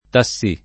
vai all'elenco alfabetico delle voci ingrandisci il carattere 100% rimpicciolisci il carattere stampa invia tramite posta elettronica codividi su Facebook tassì [ ta SS&+ ] s. m. — anche taxi [ t # k S i ], spec. nell’uso scritto; e solo taxi nel comp. radiotaxi